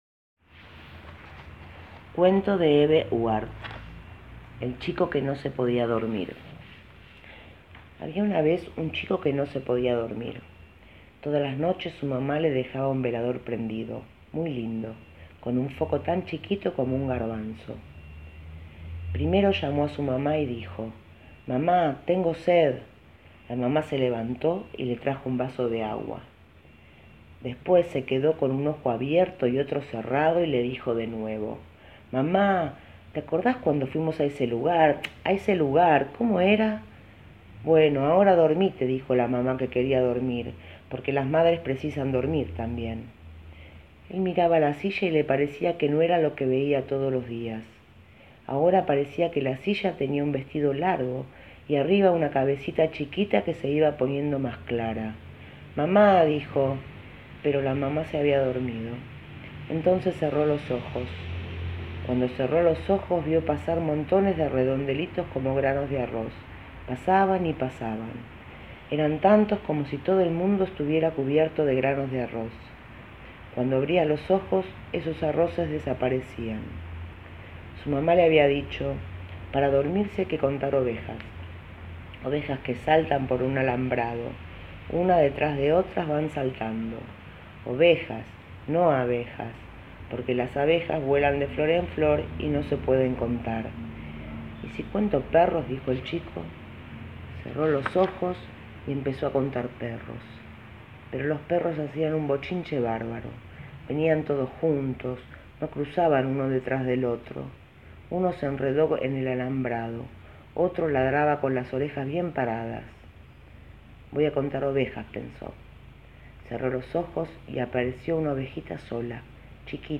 cuento